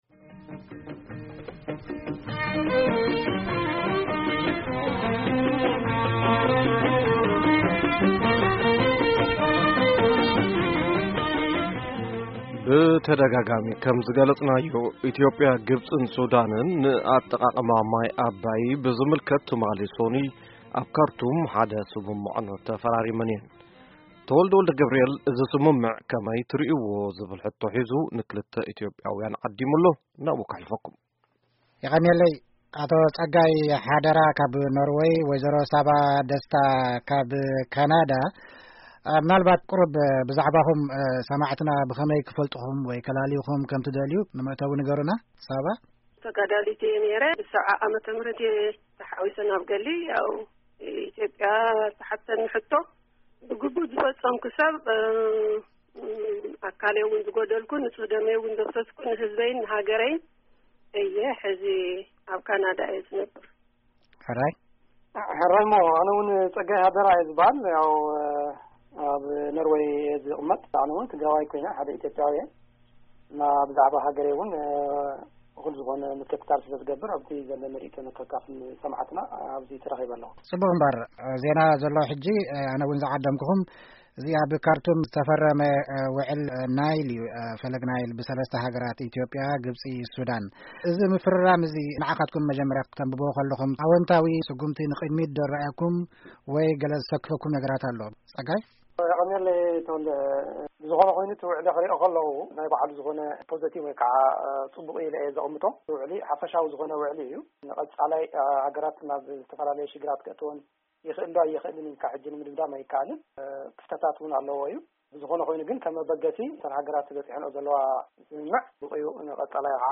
ክልተ ኢትዮጵያዊያን ንትርግም ኢትዮጵያ ምስ ግብፅን ሱዳንን ዝፈረመቶ ውዕል ኣጠቃቅማ ማይ ኣባይ ይዘራረብሉ!
ሙሉእ ቃለ-መጠይቅ ምስ ክልቲኦም ኣጋይሽ!